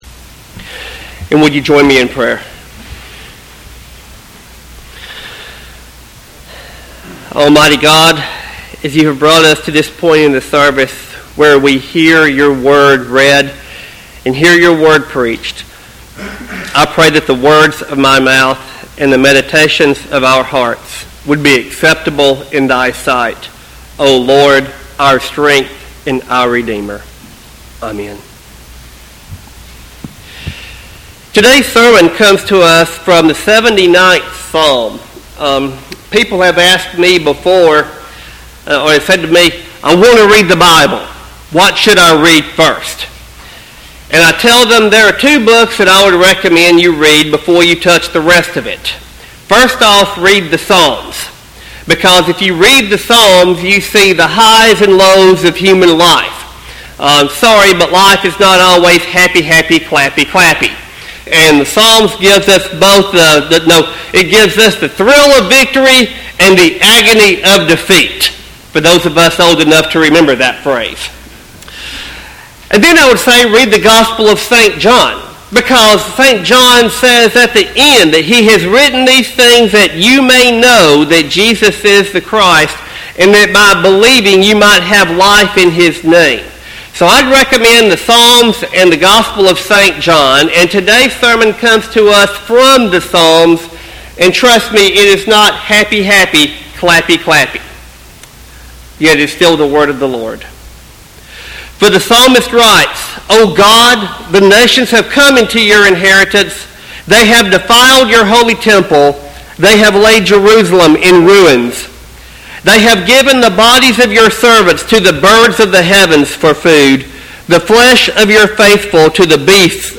Sermon text: Psalm 79.